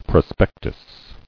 [pro·spec·tus]